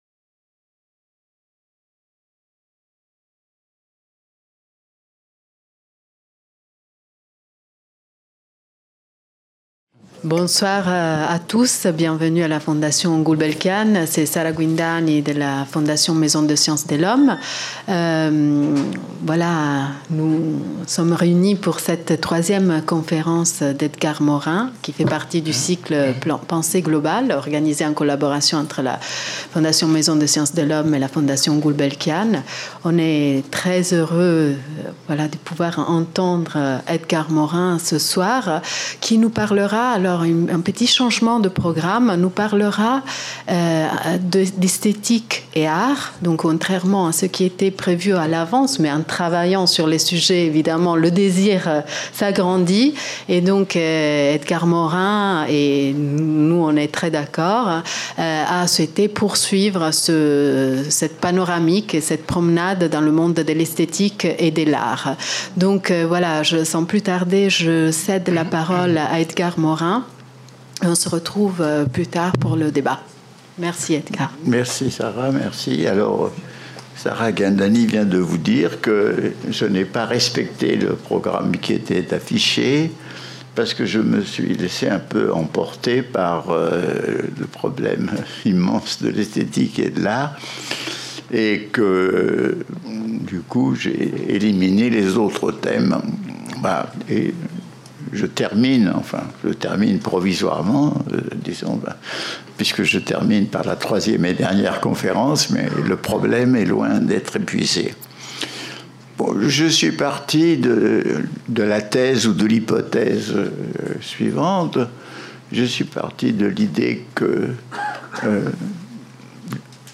Cycle de conférences Edgar Morin : Globalité et complexité (3/3) Organisé par le Collège d'études mondiales et la Fondation Calouste Gulbenkian.